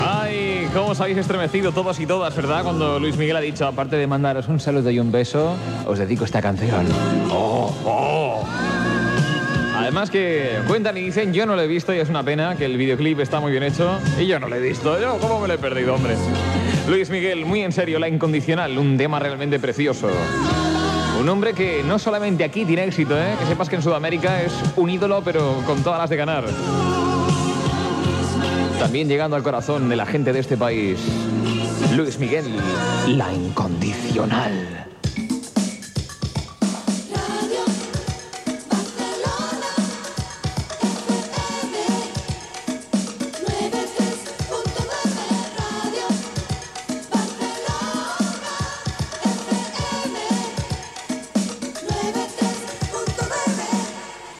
Comiat d'un disc de Luis Miguel i indicatiu de l'emissora
Musical